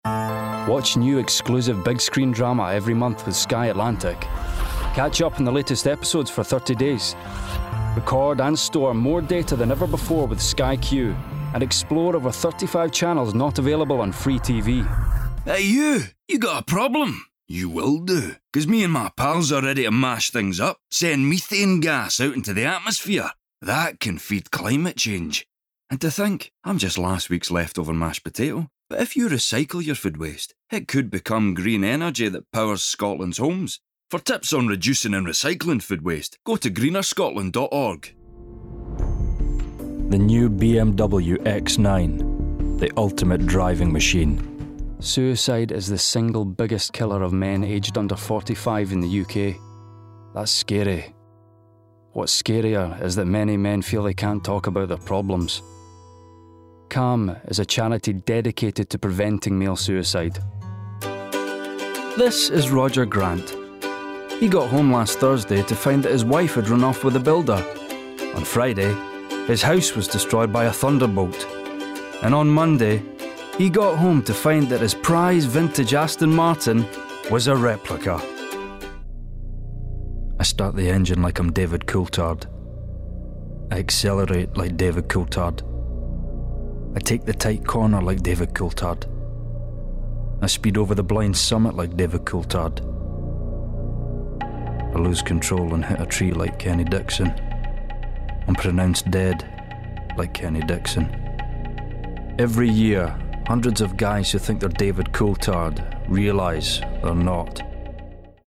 STYLE: Audio Book
20/30's Scottish, Strong/Dramatic/Gravitas